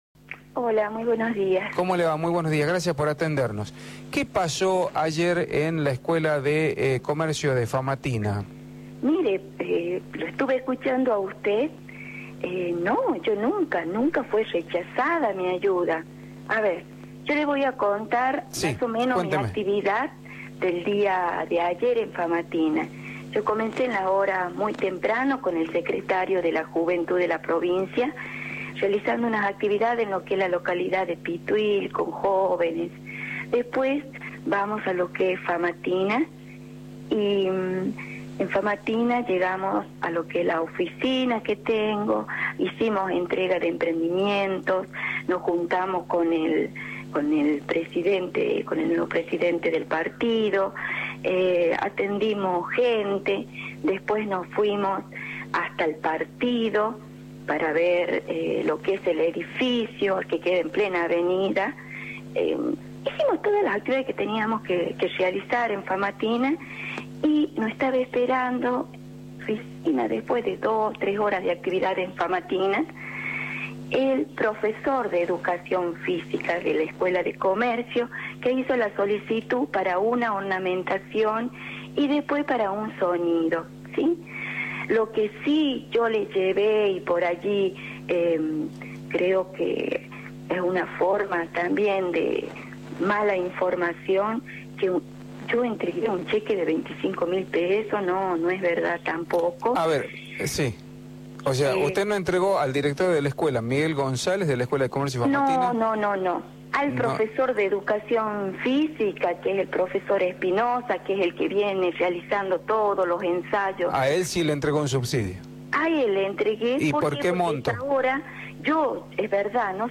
Adriana Olima, diputada por Famatina, por Radio La Red